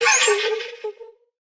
Minecraft Version Minecraft Version snapshot Latest Release | Latest Snapshot snapshot / assets / minecraft / sounds / mob / allay / item_given4.ogg Compare With Compare With Latest Release | Latest Snapshot
item_given4.ogg